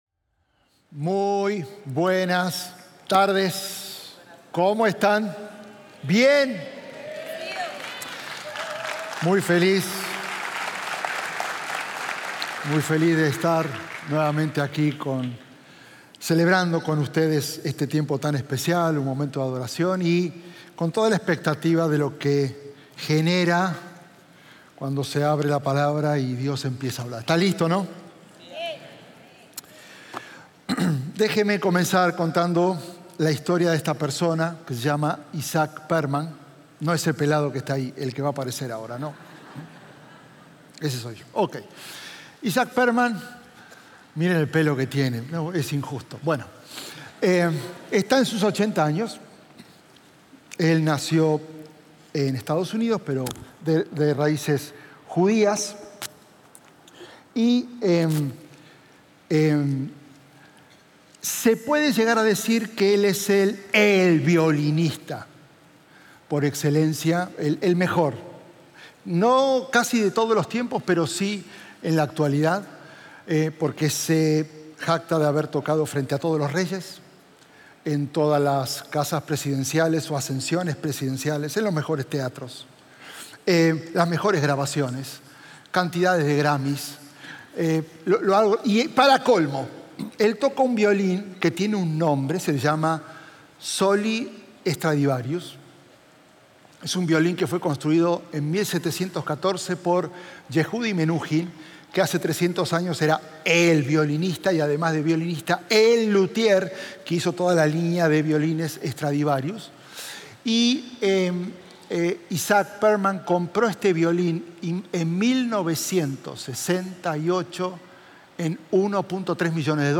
Un mensaje de la serie "Mas Fuerte."